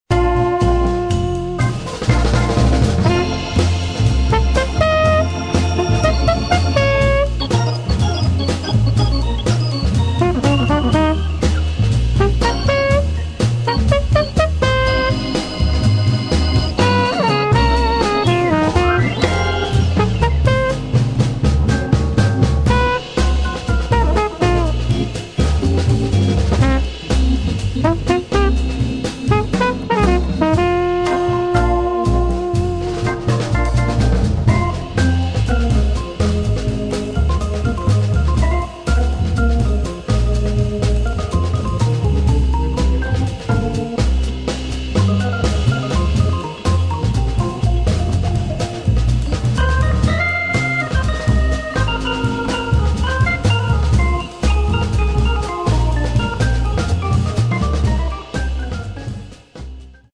[ JAZZ ]